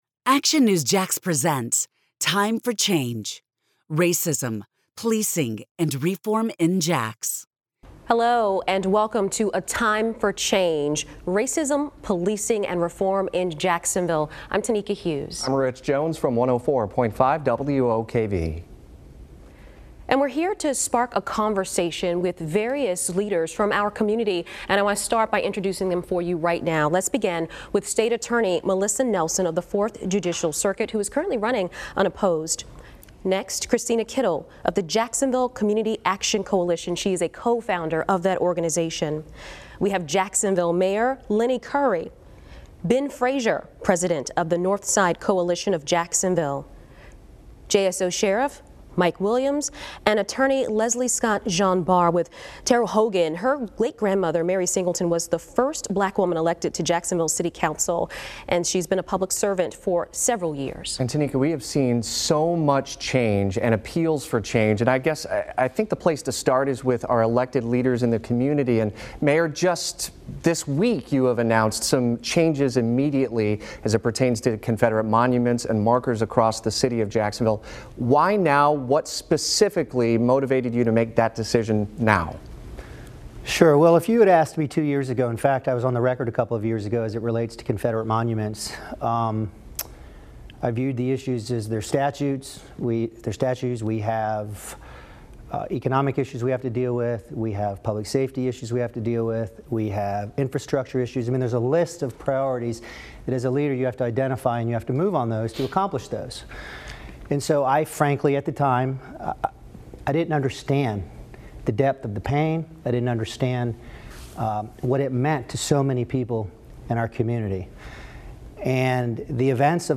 Jacksonville, FL — WOKV partnered with our Cox Media Group radio and TV stations for a town hall on racism, policing and reform in Jacksonville. Both the Sheriff and State Attorney agreed to a ‘date certain’ policy on the release of body camera footage in police-involved shootings. Jacksonville's Mayor, Sheriff, State Attorney, and leaders from the black community also tackled budget priorities and system racism during an hour-long conversation.